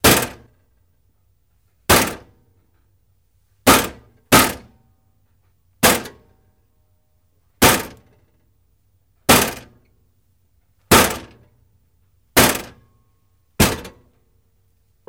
Coke Machine Hits Bigger